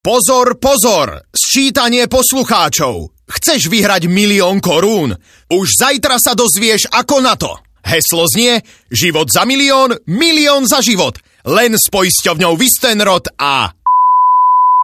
Naša najpočúvanejšia stanica Rádio Expres nevedomky vysielala kampaň na miliónovú súťaž Fun Radia. Niekoľko dní sa vo vysielaní Expresu objavoval cenzurovaný spot [
mp3 ukážka] v rôznych verziách, pričom vypípané bolo práve "Fun Radio."